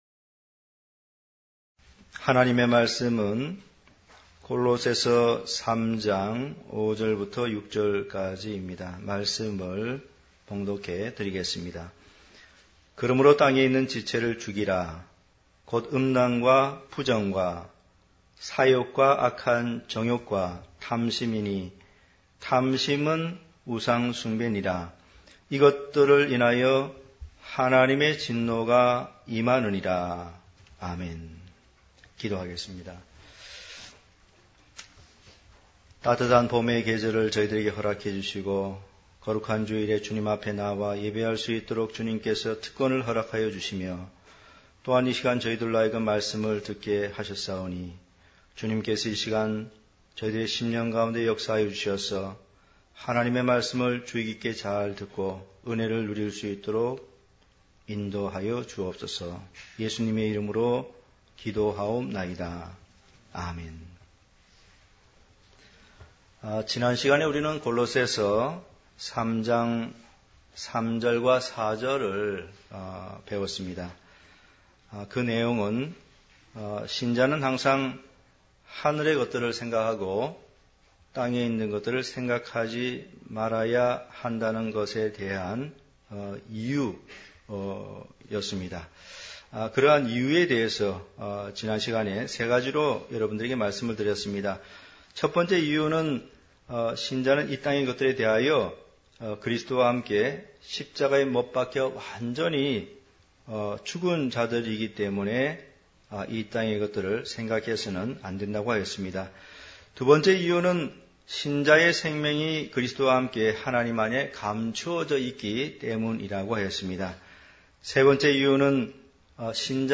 골로새서강해(28)-땅에 있는 지체를 죽이라 > 강해설교 | 진리교회